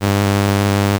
Här motsvaras källan (stämbanden) av en grundton med frekvensen cirka 100 Hz.